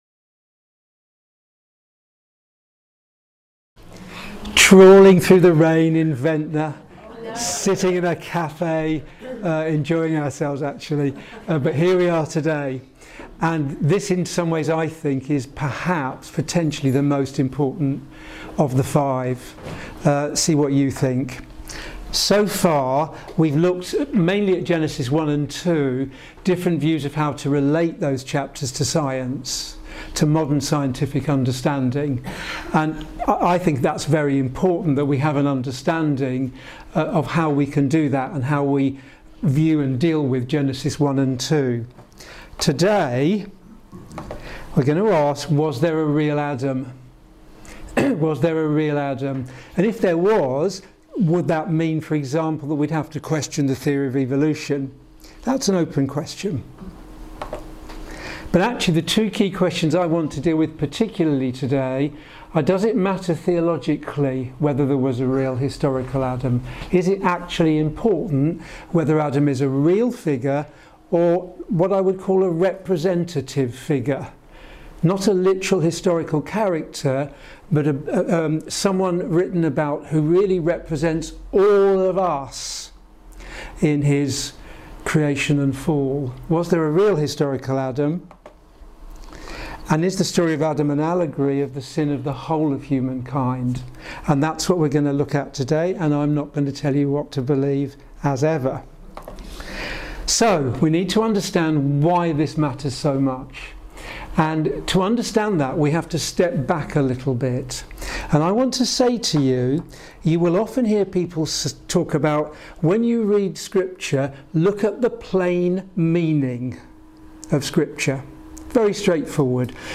Talks and Sermons - Thornhill Baptist Church